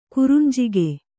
♪ kurunjige